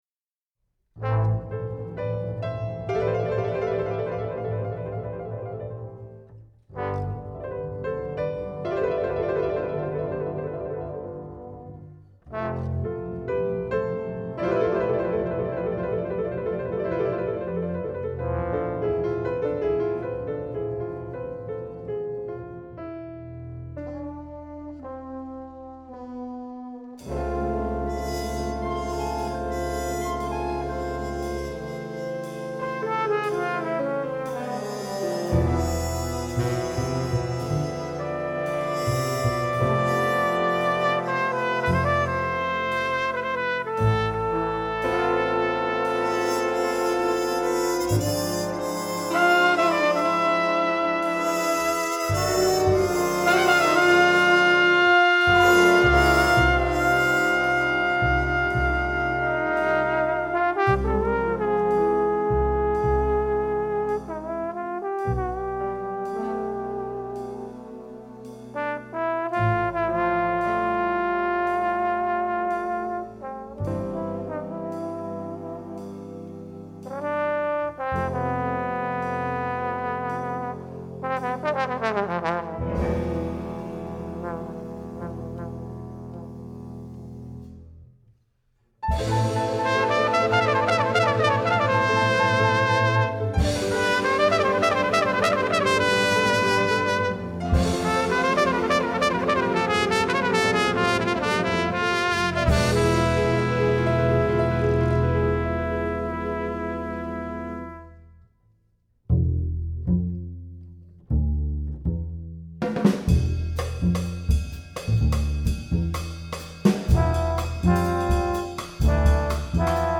Store/Music/Big Band Charts/ORIGINALS/PORTRAIT
Lead Trumpet Range: high F#
Doubles: flute, clarinet
Solos: trumpet, trombone